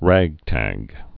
(răgtăg)